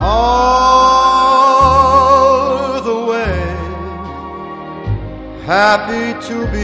• Jazz Ringtones